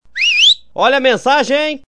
olha a mensagem classico Meme Sound Effect